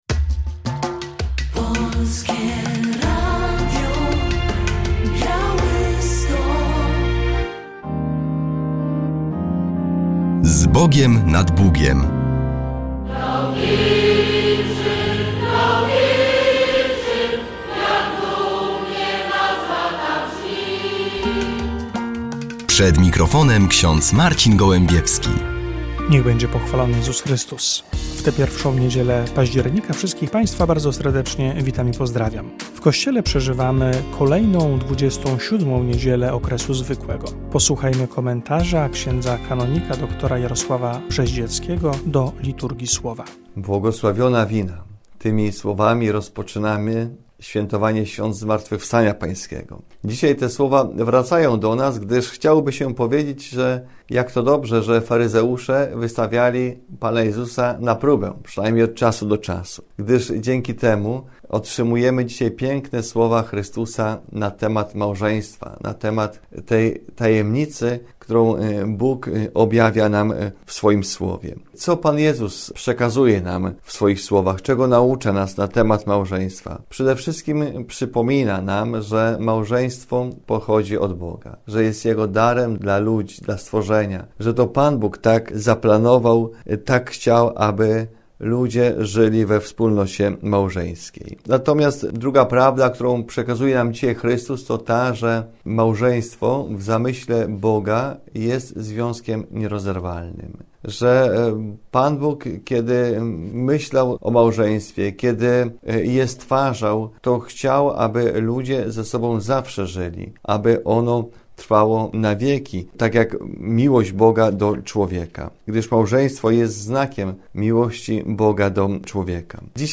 W magazynie relacja z inauguracji roku akademickiego w Wyższym Seminarium Duchownym w Drohiczynie.